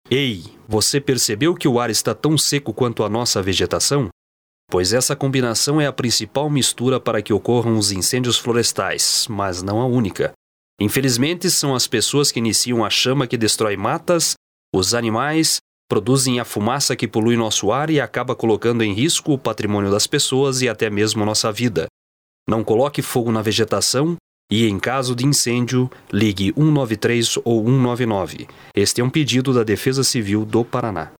Várias rádios receberam o spot e estão difundindo as informações da campanha durante sua programação.